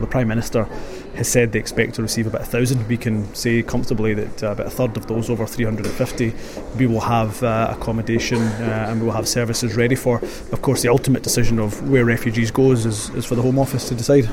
International Development Minister Humza Yousaf tells us Scotland could welcome 350 refugees by Christmas